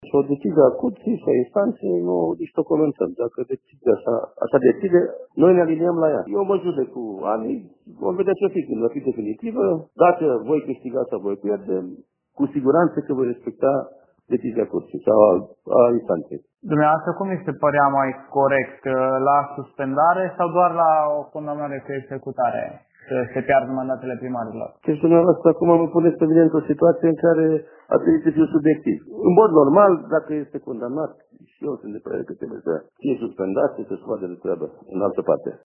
Trimis în judecată pentru conflict de interese, primarul din Deta susține că decizia Curții Constituționale este corectă: